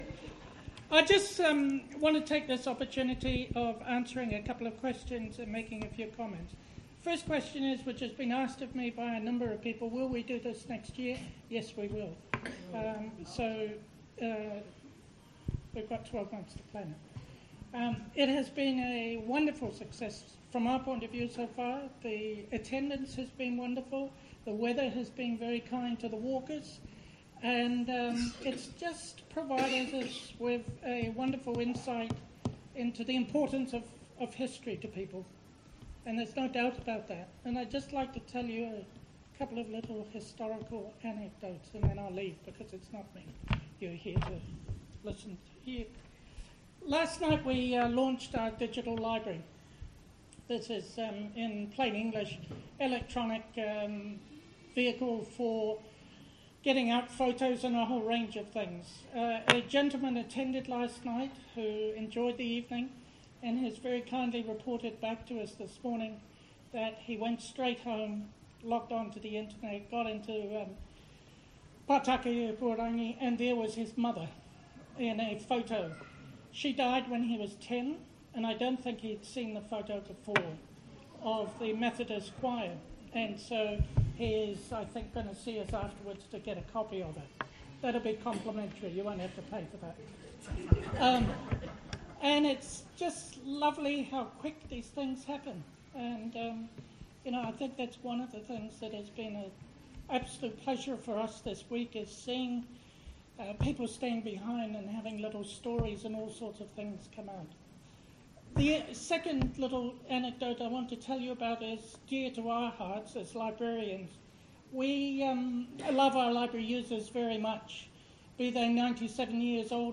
– Talk - Manawatū Heritage